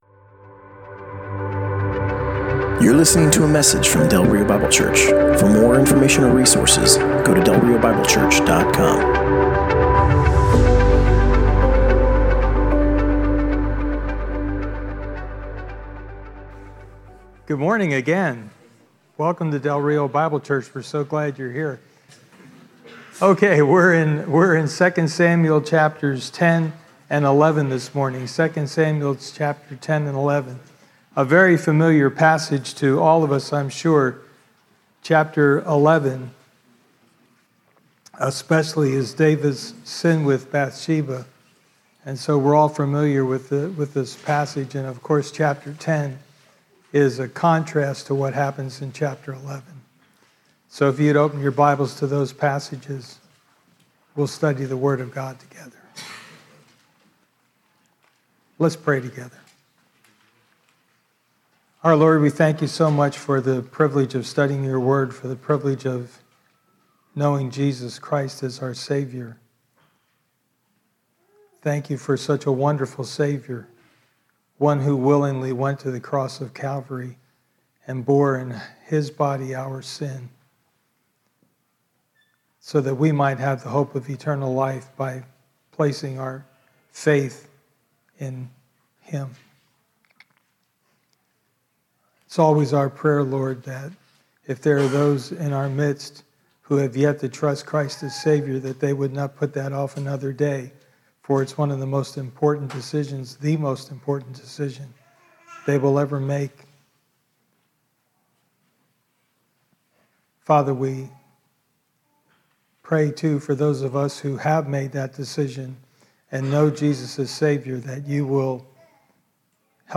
Passage: 2 Samuel 10:1 - 11:27 Service Type: Sunday Morning